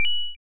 CoinPickup.wav